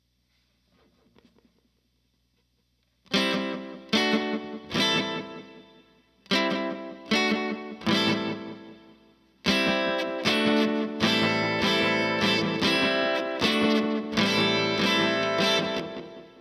Guitar_Cowboys_152bpm_Emin.wav